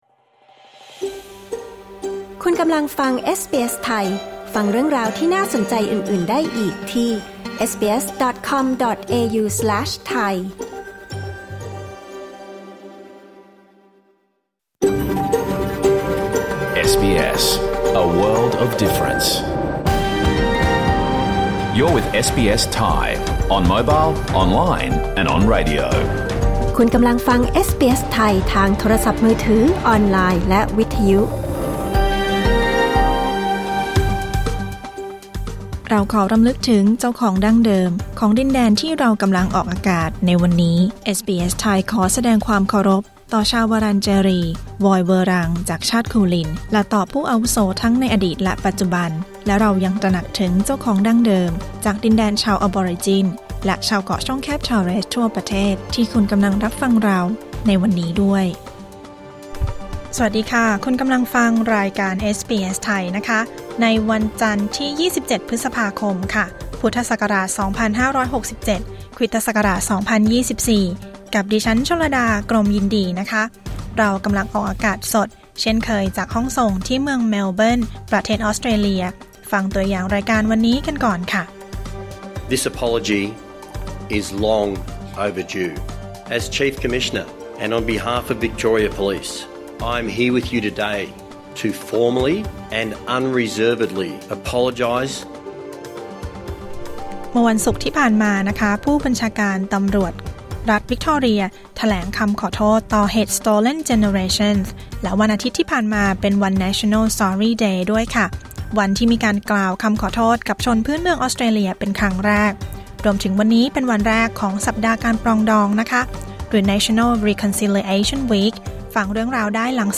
รายการสด 27 พฤษภาคม 2567